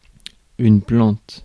Ääntäminen
Synonyymit végétal Ääntäminen France: IPA: /plɑ̃t/ Haettu sana löytyi näillä lähdekielillä: ranska Käännös Ääninäyte Substantiivit 1. plant US 2. sole US 3. sole of the foot 4. lavender Suku: f .